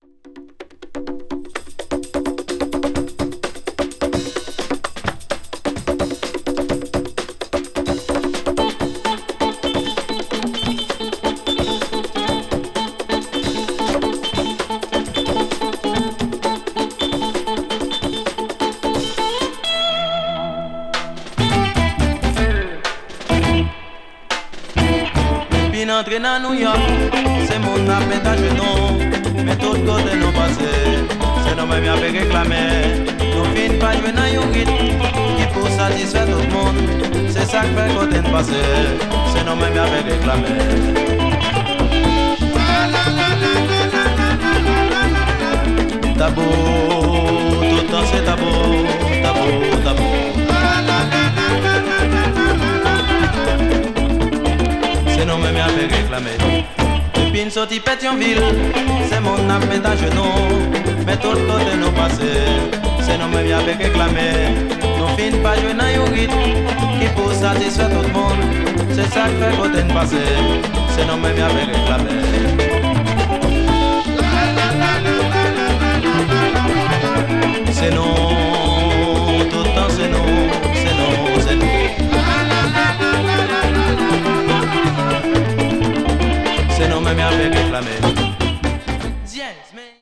media : EX/EX(わずかにチリノイズが入る箇所あり)
carib   compas   haiti   world music